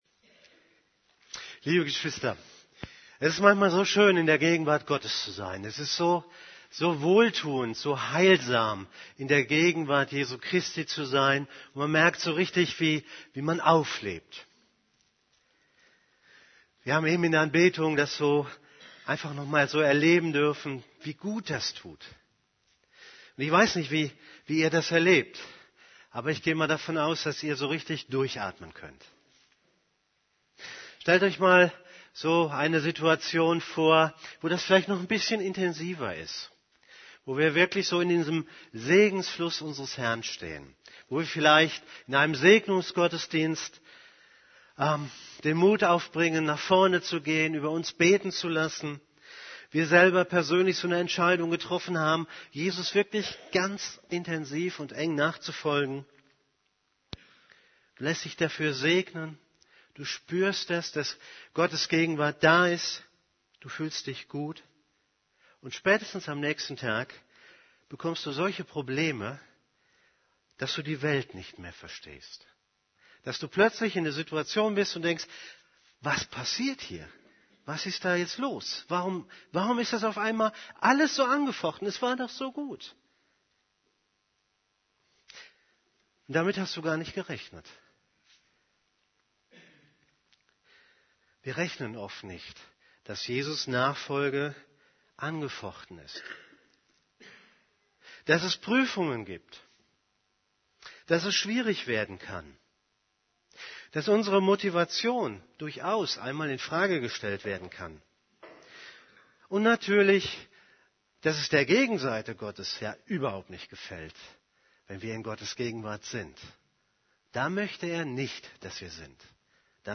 > Übersicht Predigten Du bist stark durch Gottes Ausrüstung Predigt vom 15.